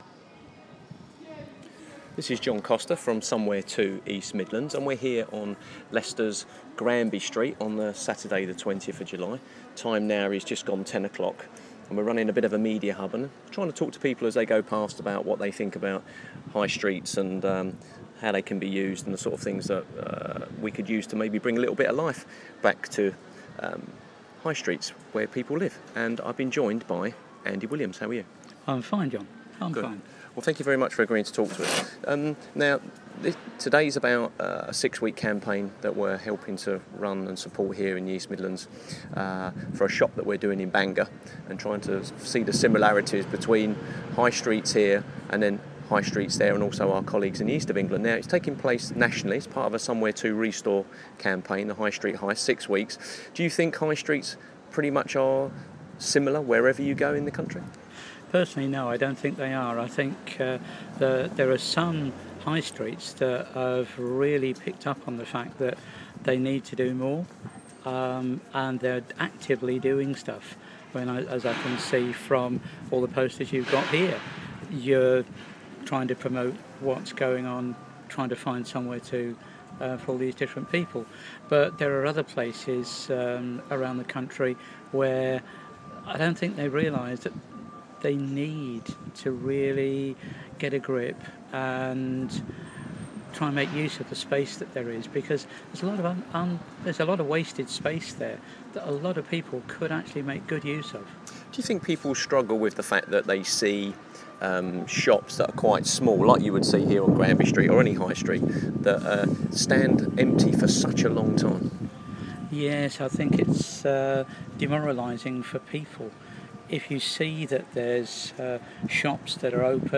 somewhereto_ re:store Media Hub: So what is the future of the High Street? Interview